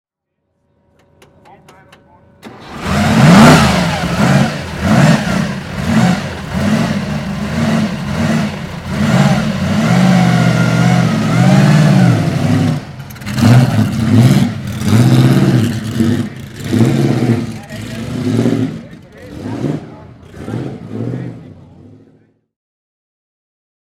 GP Mutschellen 2012 - es muss nicht immer Goodwood sein (Veranstaltungen)
Bugatti T 54/50B (1936) - Starten und Wegfahrt
Bugatti_Monoposto_1938.mp3